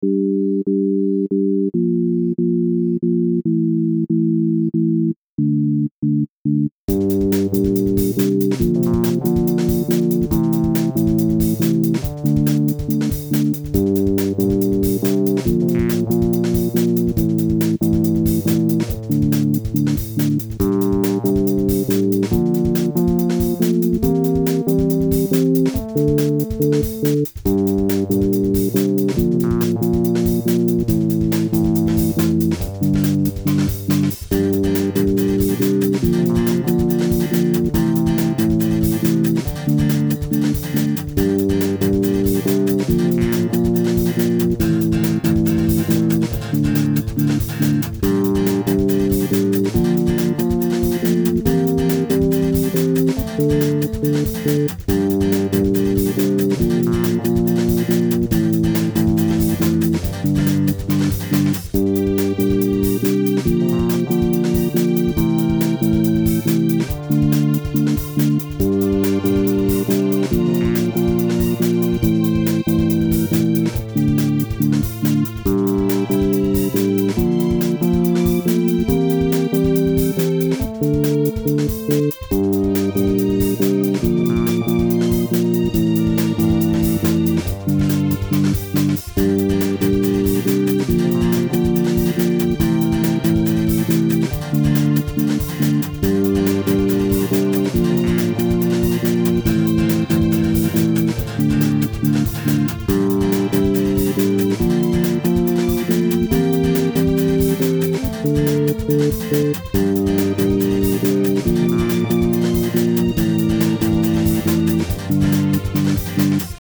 Details: Duration: 1:56 Program: Reason 3.0 Key Instruments: Synth, Guitar, and Violin